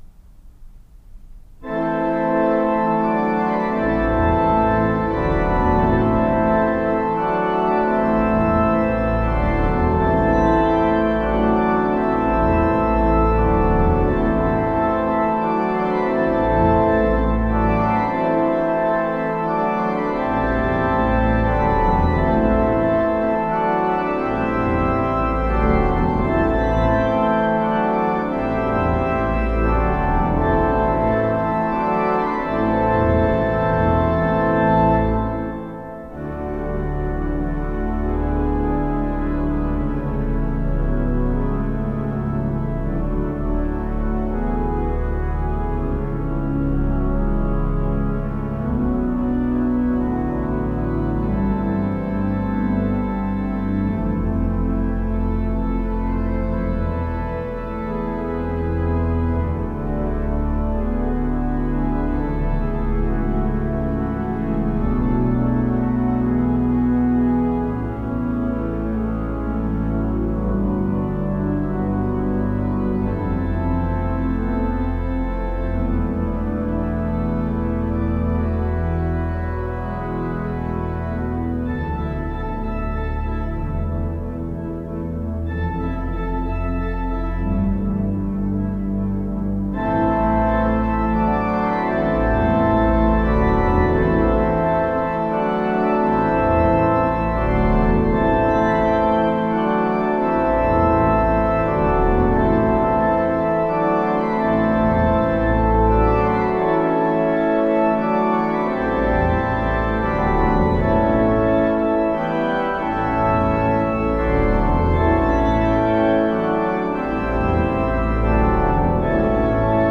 Voicing: Org 3-staff